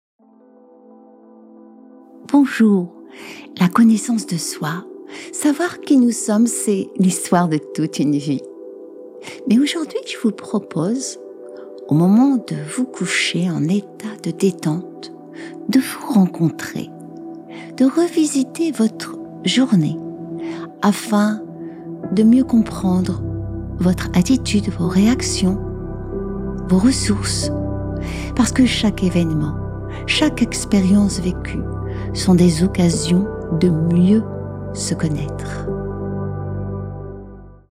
Relaxation guidée – Connaissance de soi – Une rencontre avec soi-même